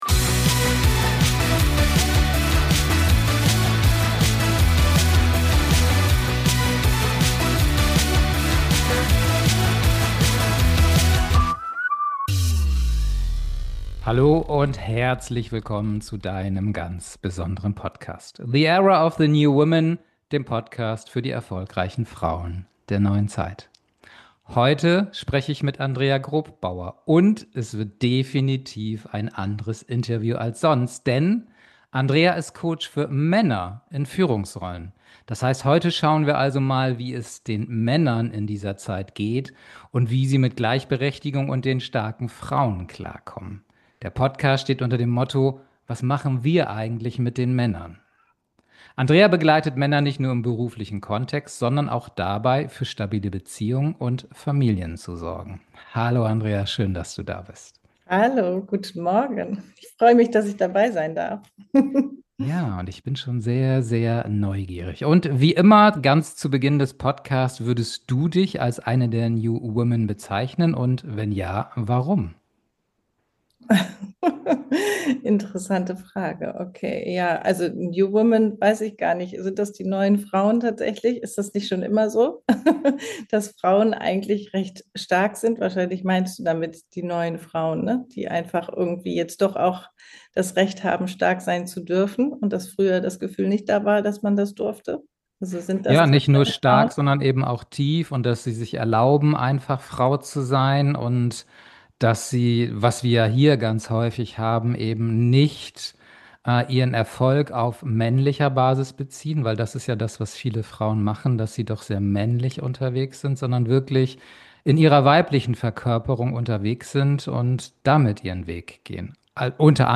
#066 Das Schweigen der Männer: Stärke oder Flucht? Das Interview